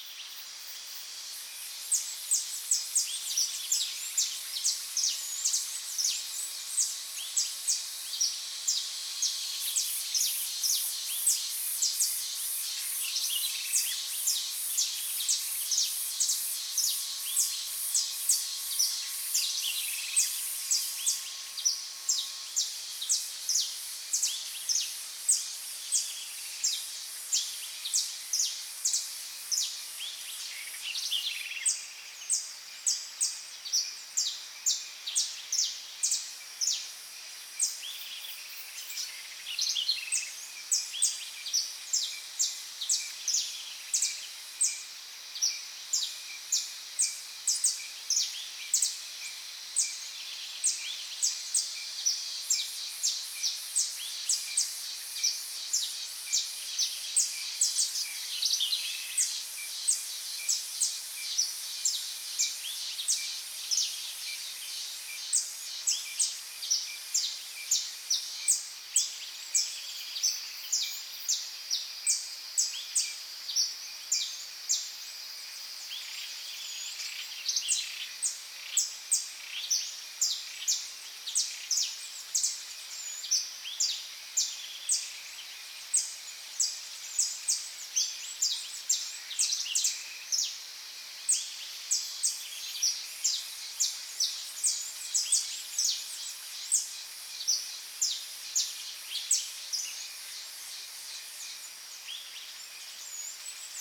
OlderJungleAmbience.ogg